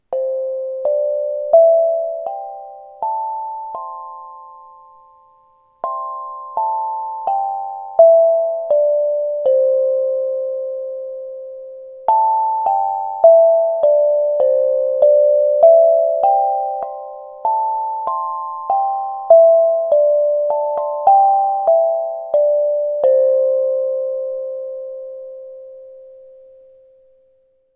NINO Percussion Mini Melody Steel Tongue Drum - 6" (NINO980R)
Thanks to its harmonic tone sequence, there are no wrong-sounding notes. The sound is warm and soothing (like a soft steel pan drum). Two soft mallets with rubber heads are included.